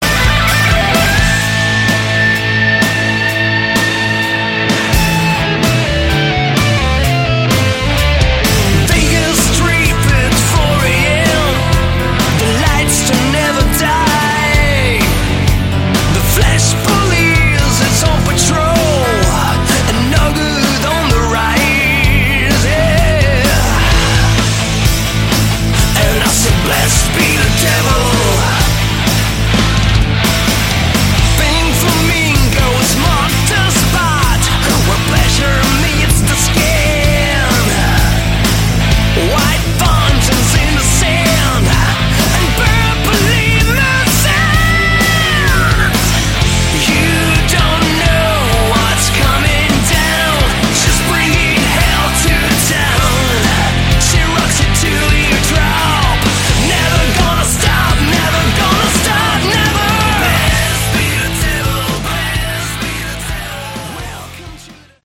Category: Melodic Hard Rock
guitar, backing vocals
bass, backing vocals
drums